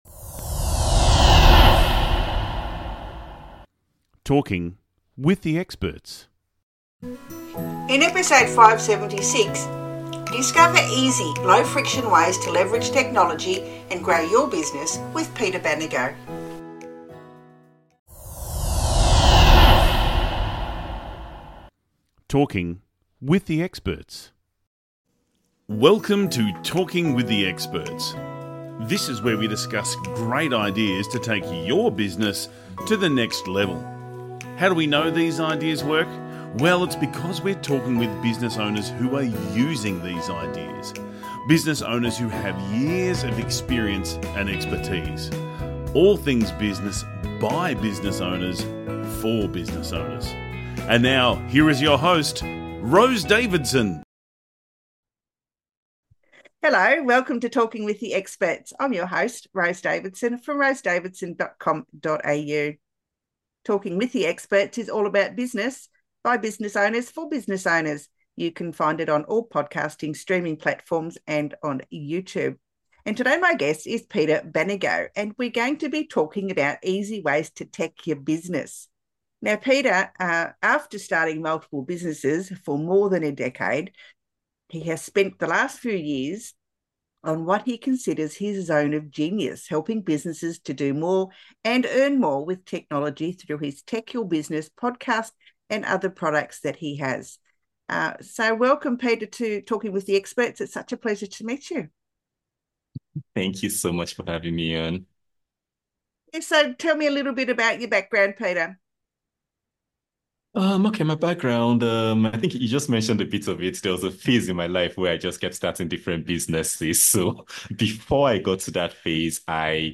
this enlightening conversation